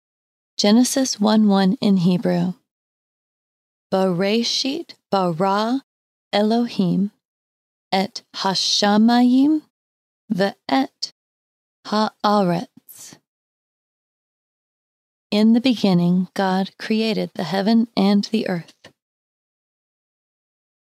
Sample Hebrew:
19-Genesis-1_1-in-Hebrew-slowly.mp3